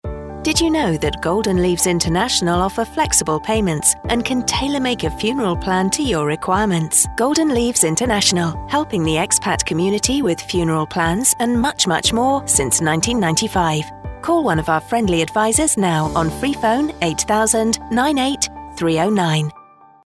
Radio Adverts"Did You Know?..."
Golden-Leaves-APR18-Funeral-2-FEM-20.mp3